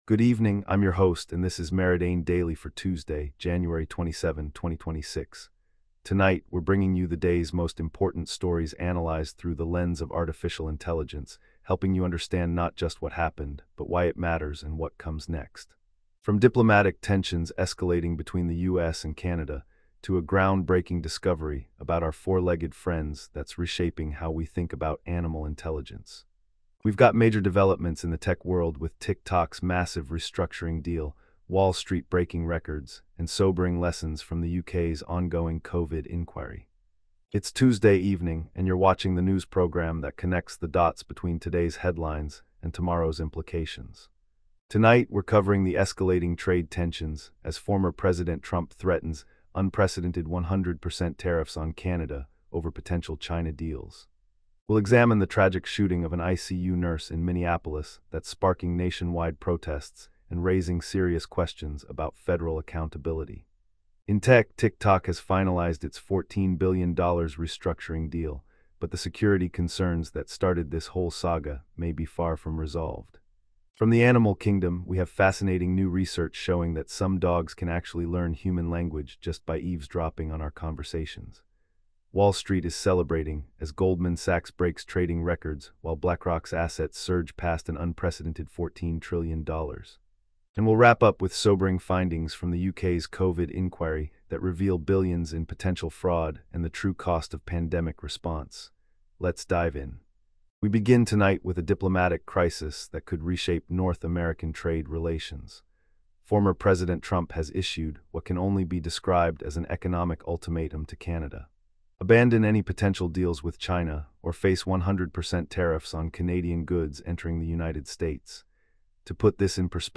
Your nightly AI-powered news briefing for Jan 27, 2026